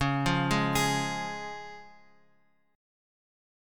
C# chord {x 4 3 1 x 4} chord
Csharp-Major-Csharp-x,4,3,1,x,4-8.m4a